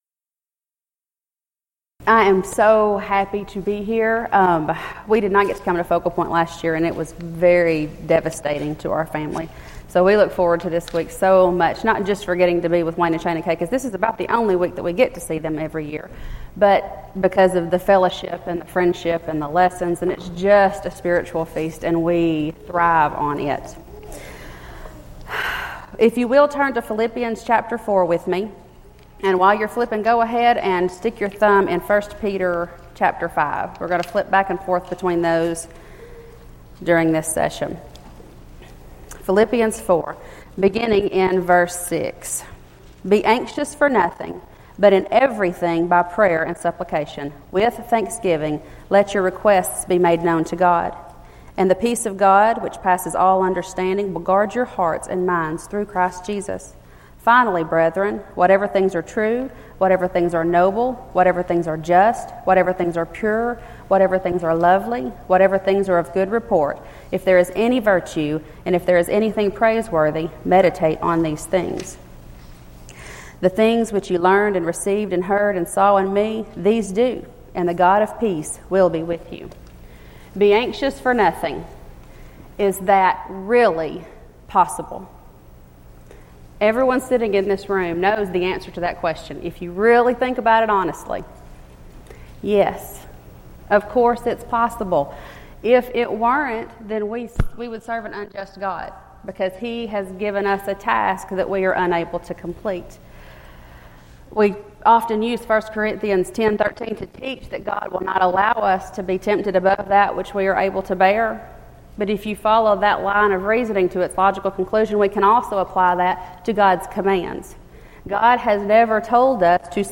Ladies Sessions